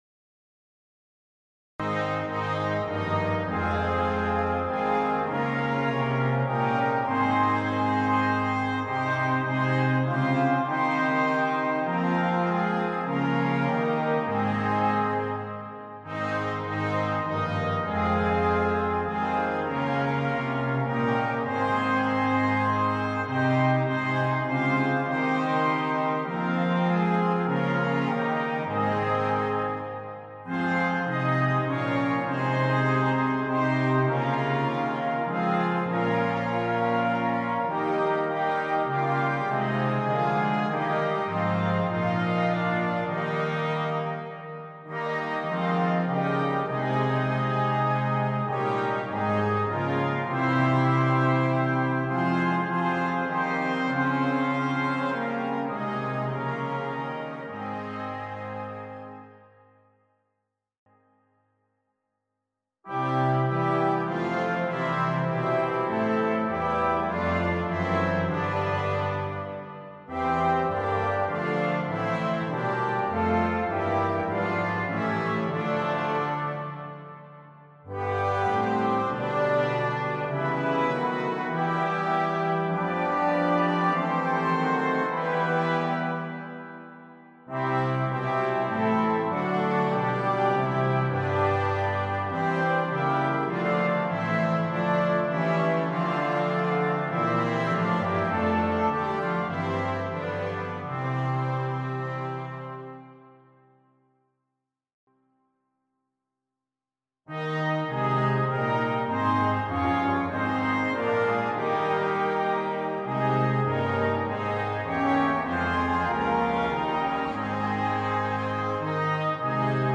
Besetzung Blasorchester